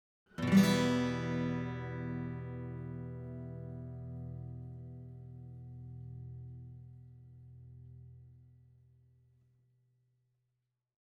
i-dont-know-acguitar-with-drum-click-bleed_rx-wav.90223